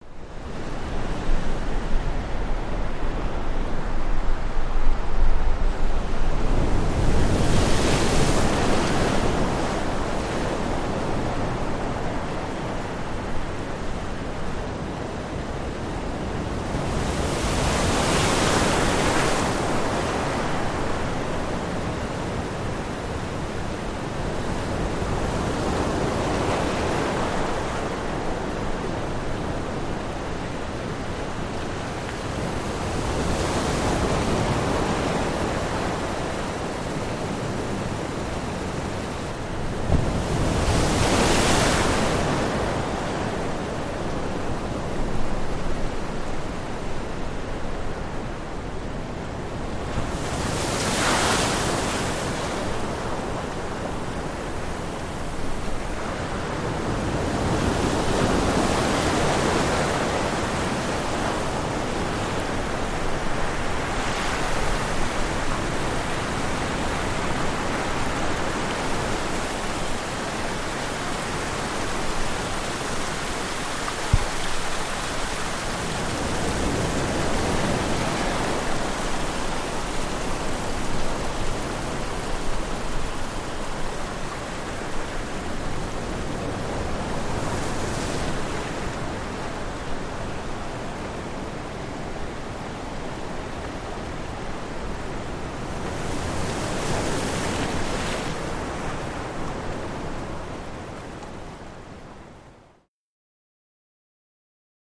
Wave Crashes
Close Up On Mediterranean Sea, Waves Break On Rocks, Some Splash.